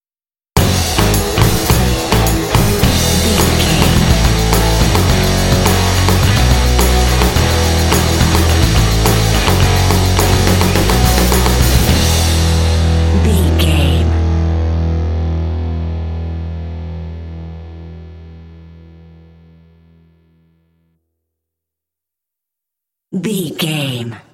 This rock track is great for action and sports games.
Epic / Action
Uplifting
Ionian/Major
motivational
determined
energetic
lively
electric guitar
bass guitar
drums
alternative rock
indie